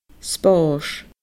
spòrs /sbɔːRs/